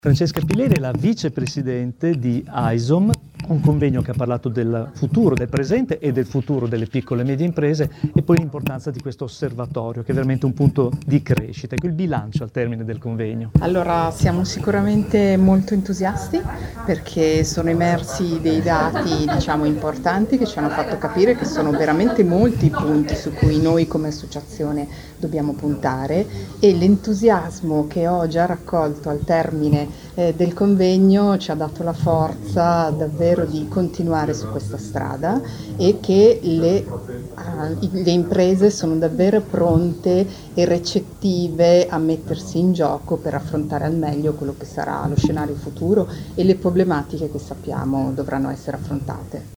Lieti di essere main sponsor di questo importante evento, “Strategia per il rinascimento delle PMI”, organizzato AICIM e AISOM presso il Grand Hotel Magestic già Baglioni di Bologna.
Intervista a